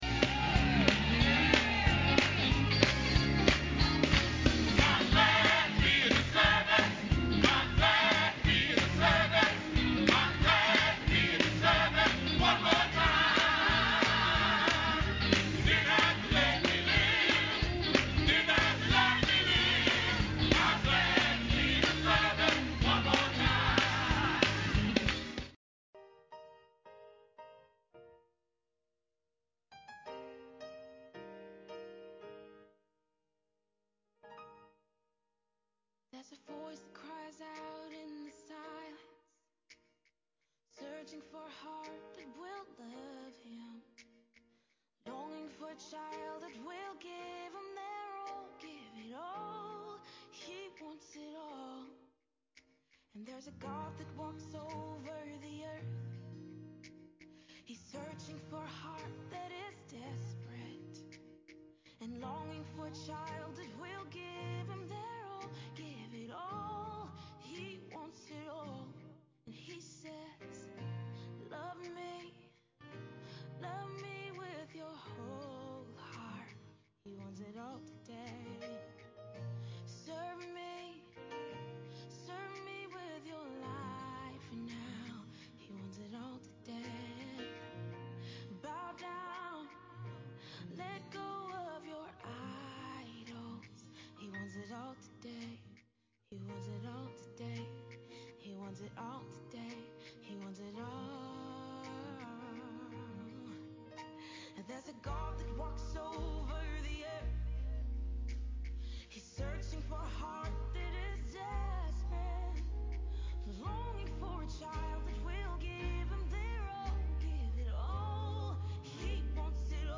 7:30 A.M. Service: Listening To Jesus